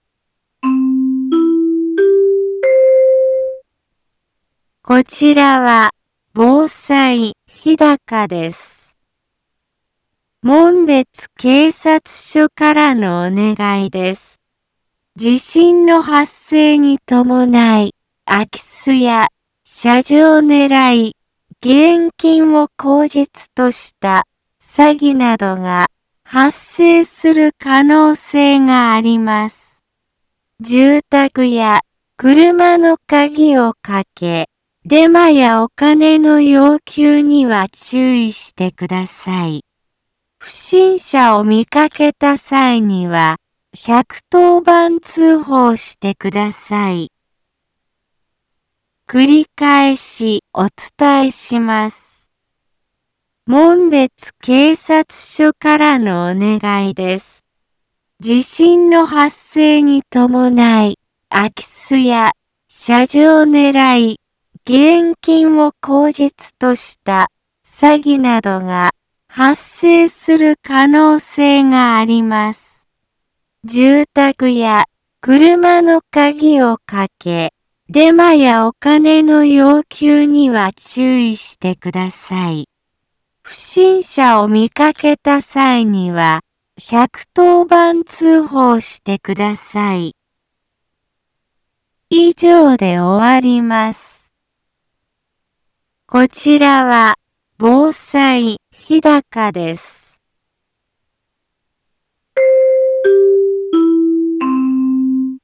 一般放送情報
Back Home 一般放送情報 音声放送 再生 一般放送情報 登録日時：2018-09-13 16:03:51 タイトル：詐欺のご注意 インフォメーション：門別警察署からのお願いです。 地震の発生に伴い、空き巣や、しゃじょうねらい、義援金を口実とした、詐欺などが、 発生する可能性があります。